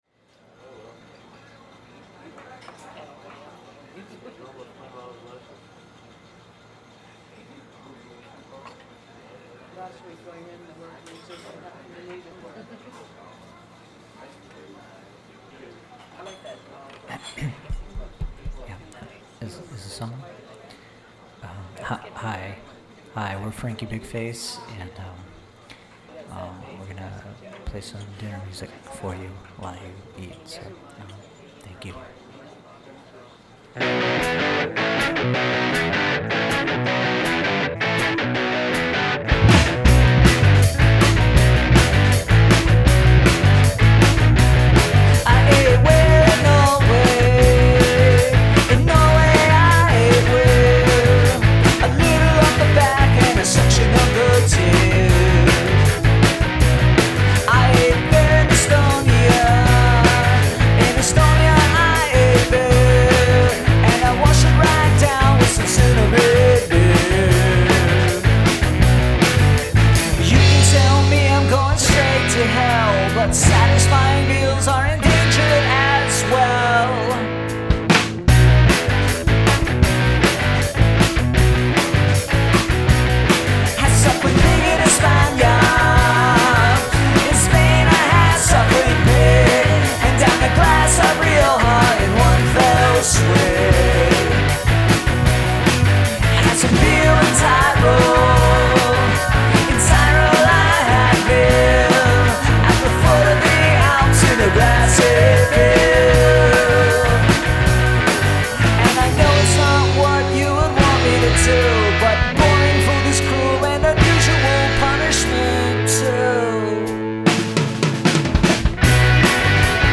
"EEEs" in second half peaking on lesser systems.